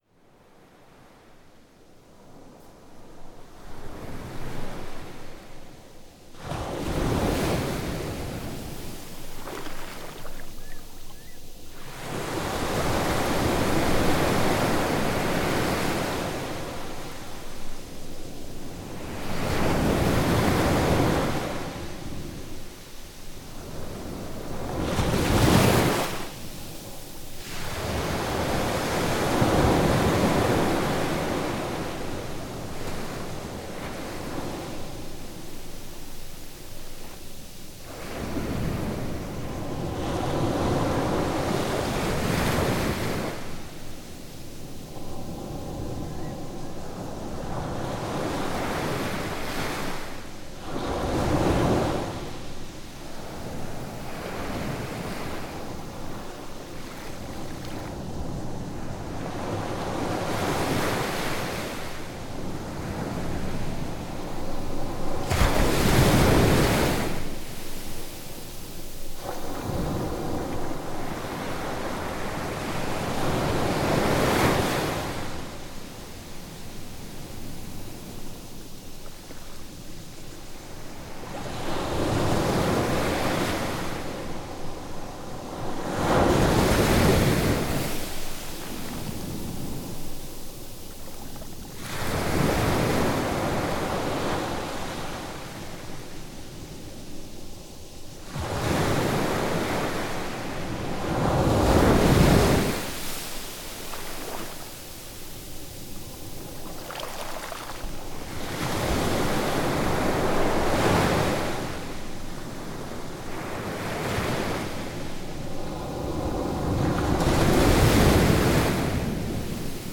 4-南澳沙灘 | 台灣聲景協會 Soundscape Association of Taiwan